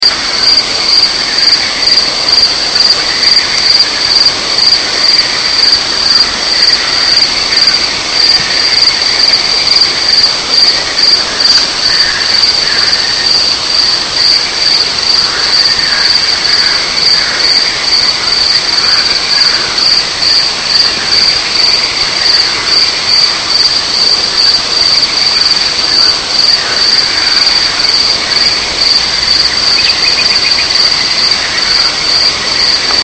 Zum krönenden Abschluß des Abend setzten wir uns noch mit einer Flasche guten Rotwein bei Kerzenschein auf unsere Terrasse, hörten den Fröschen und Grillen zu und redeten noch ein wenig über Gott und die Welt...
Frösche und Grillen...